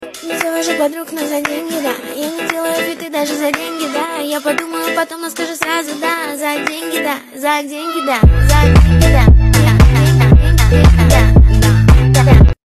Танцевальные рингтоны
Клубные рингтоны
женский голос
поп
клубняк